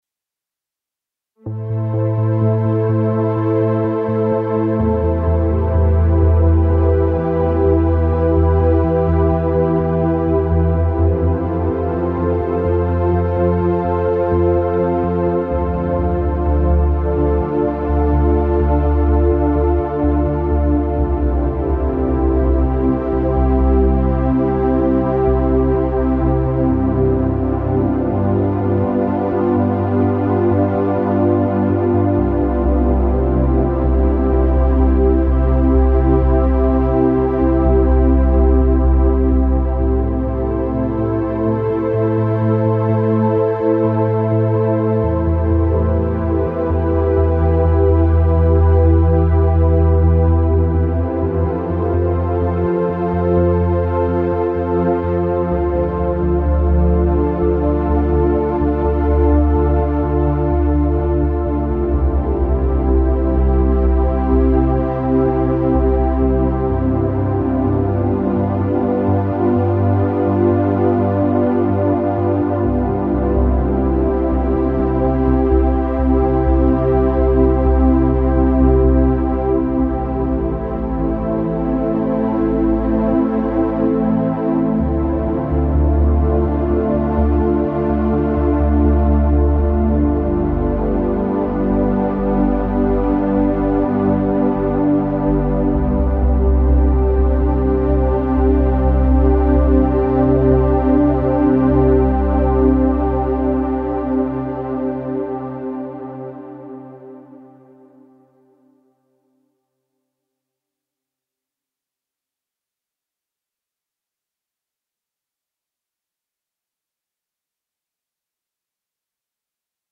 Largo [0-10] - - nappes - aerien - aquatique - drones - mer